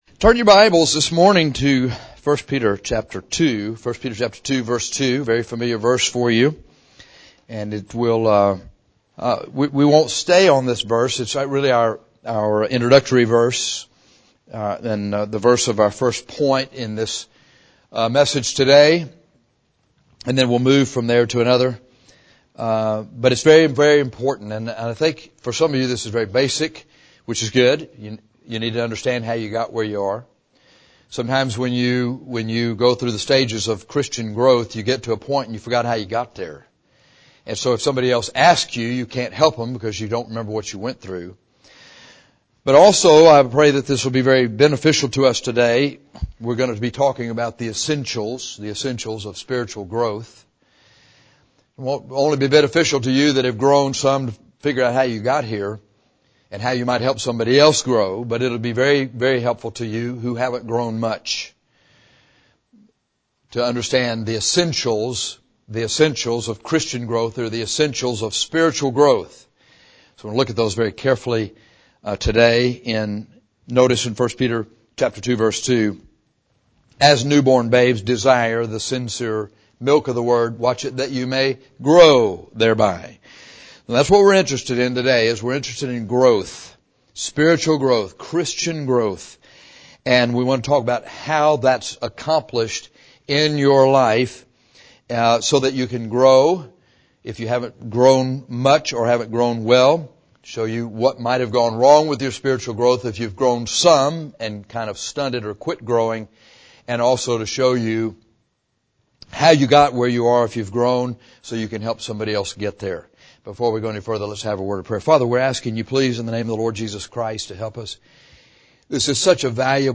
This sermon should help you with your spiritual growth, whether you have already grown some or you are newly saved.